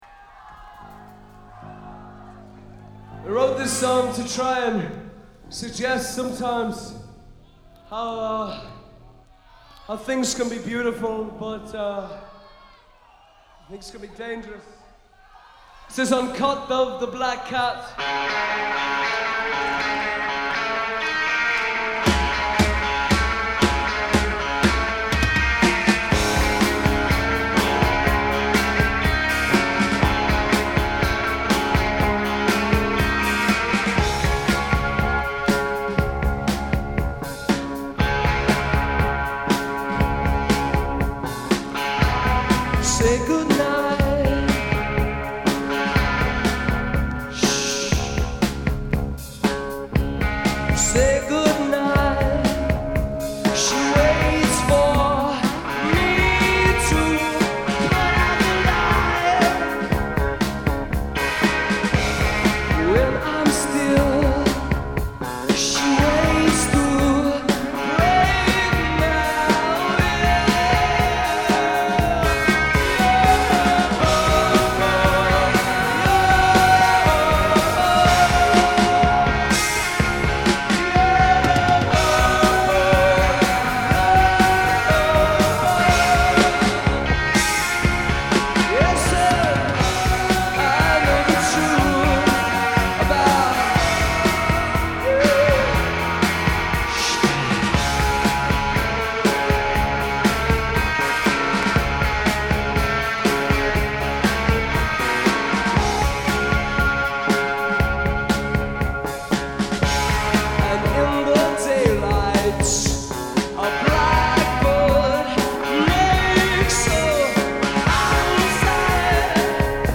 Recorded live at the Hammersmith Palais on 12/6/1982